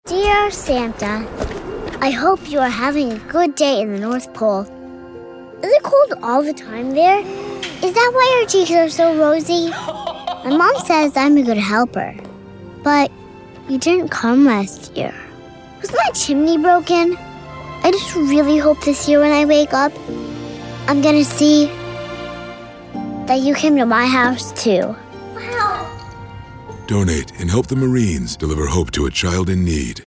Toys for Tots Digital Media Library (Radio PSAs)